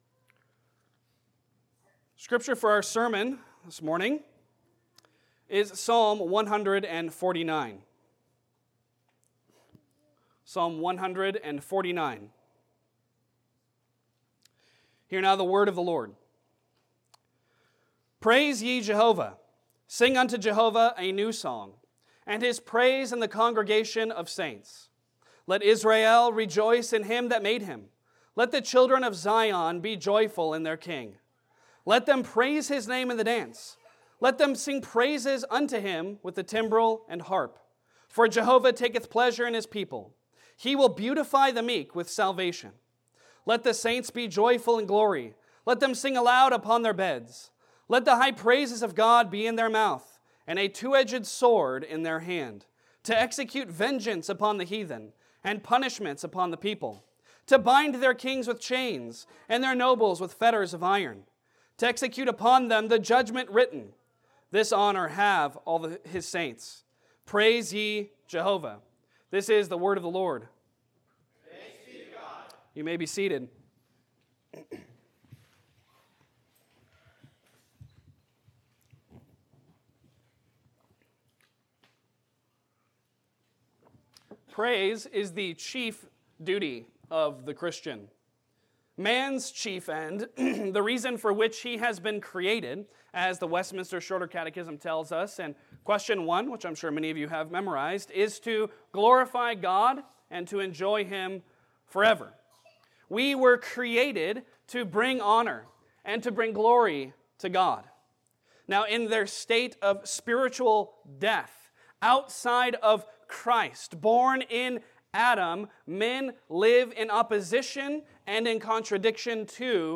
Passage: Psalm 149 Service Type: Sunday Sermon Download Files Bulletin « Baptism